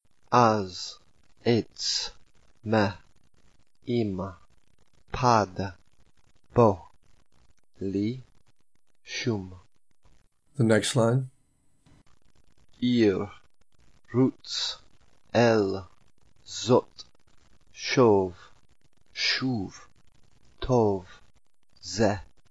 One syllable Readings:
reading practice